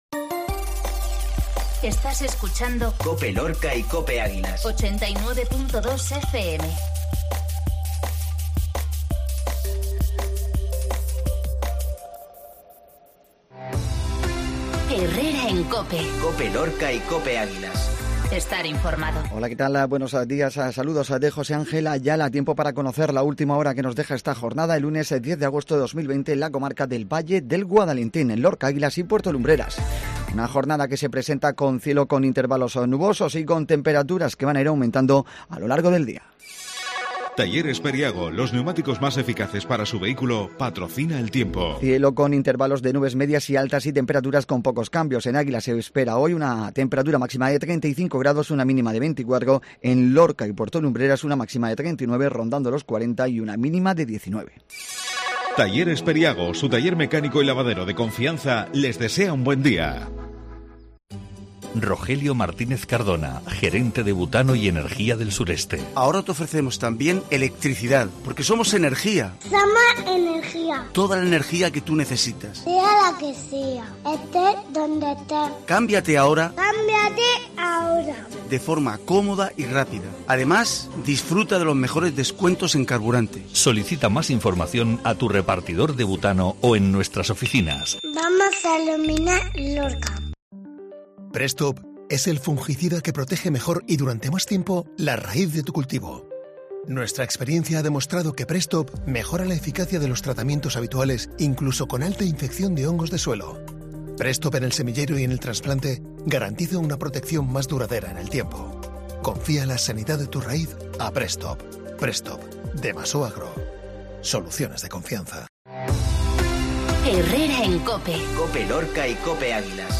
INFORMATIVO MATINAL LUNES 10008